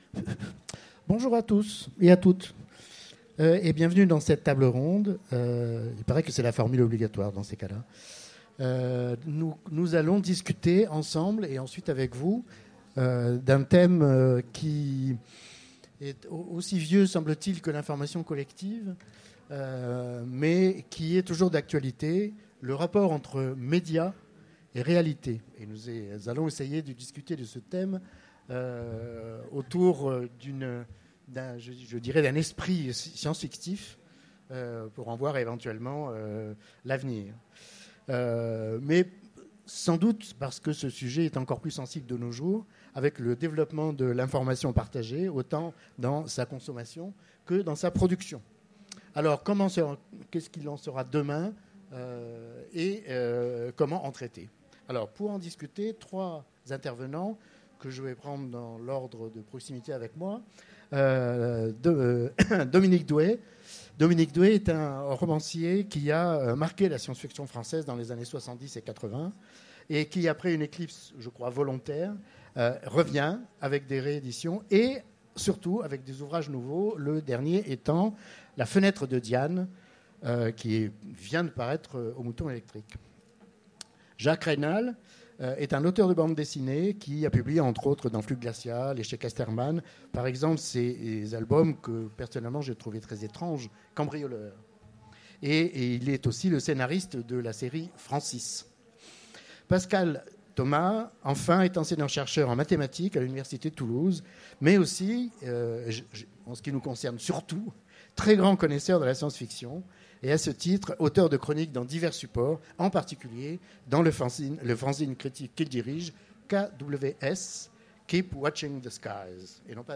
Utopiales 2015 : Conférence Médias et Réalités
Conférence